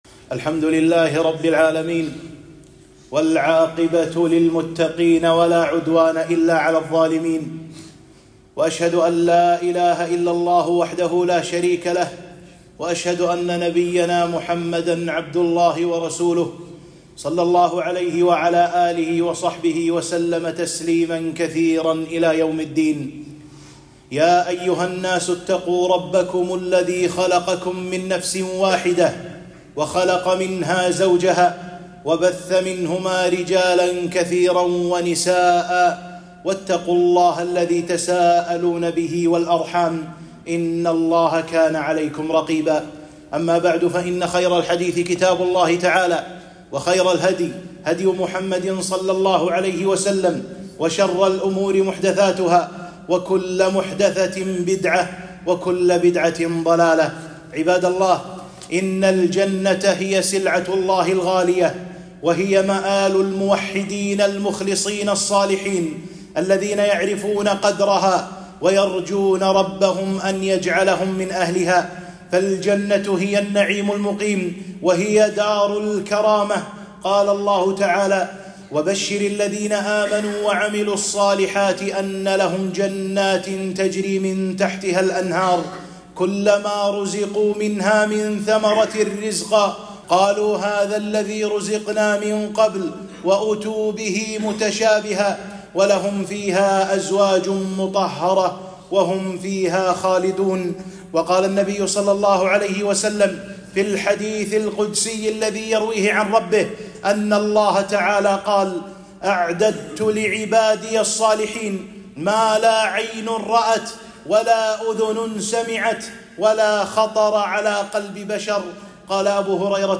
خطبة - المبادرة إلى الخير، وترك المسلم ما لا يعنيه